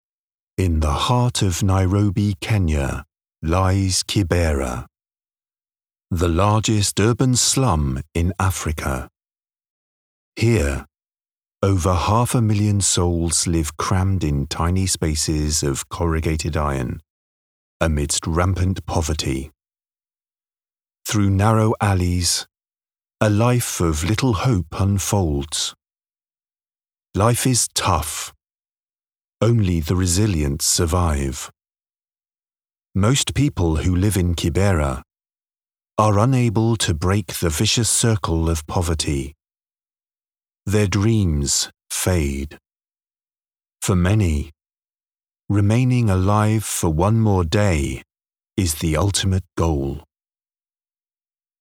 Documentaries
I have a warm and engaging English RP accent which is suitable for a wide range of projects, including audiobook, corporate and commercial.
I produce audio from my purpose built home studio where I use a Shure SM7B mic with a Focusrite Scarlet 2i4 interface and Logic Pro on a Mac.
BaritoneBass